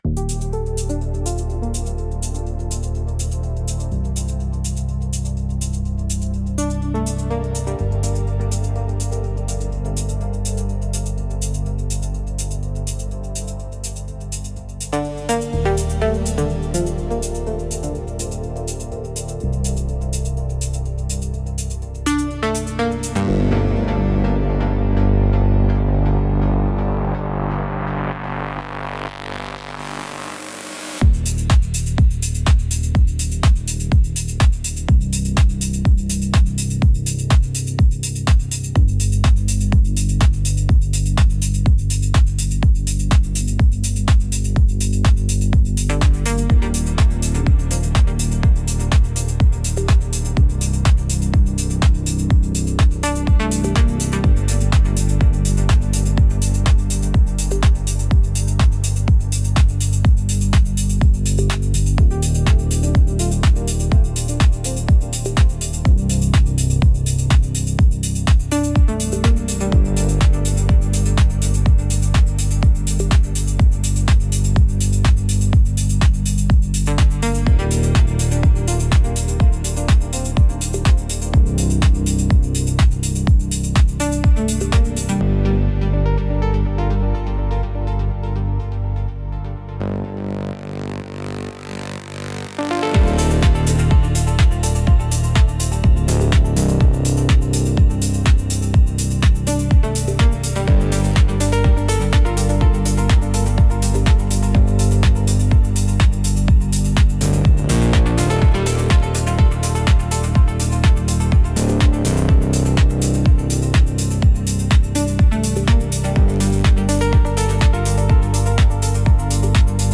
Instrumental - Ghosts in the Reverb - 6.14